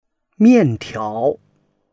mian4tiao2.mp3